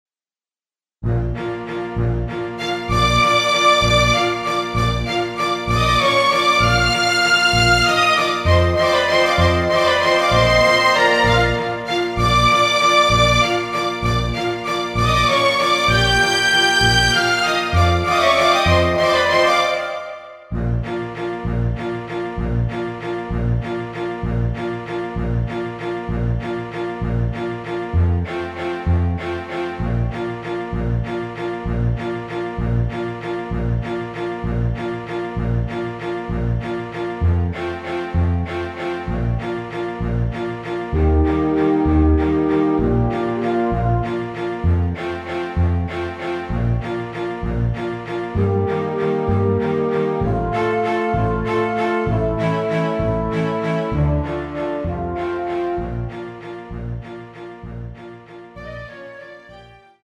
오페라
Bb
앞부분30초, 뒷부분30초씩 편집해서 올려 드리고 있습니다.
중간에 음이 끈어지고 다시 나오는 이유는